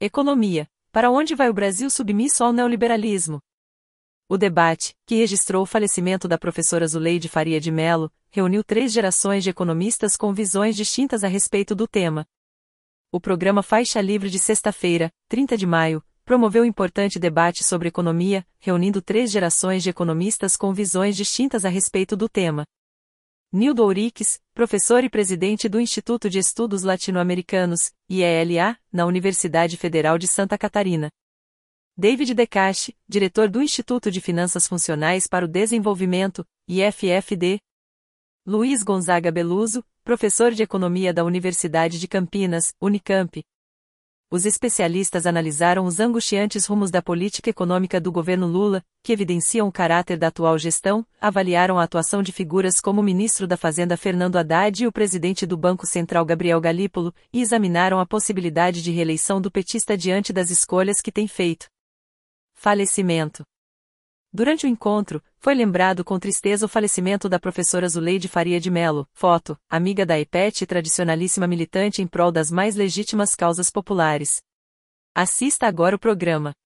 O programa Faixa Livre de sexta-feira (30/05) promoveu importante debate sobre economia, reunindo três gerações de economistas com visões distintas a respeito do tema.
– Luiz Gonzaga Belluzzo, Professor de Economia da Universidade de Campinas (Unicamp)